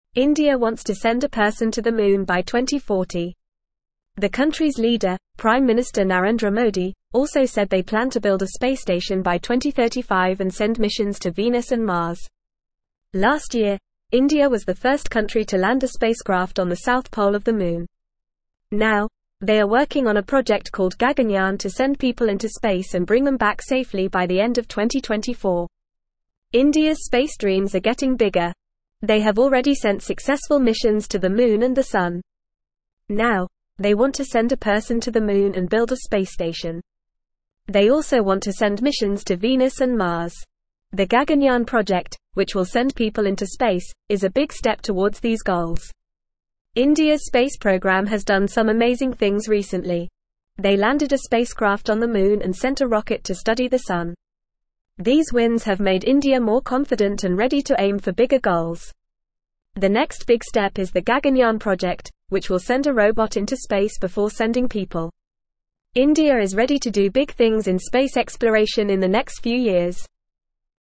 Fast
English-Newsroom-Lower-Intermediate-FAST-Reading-Indias-Big-Space-Dreams-Moon-Sun-and-More.mp3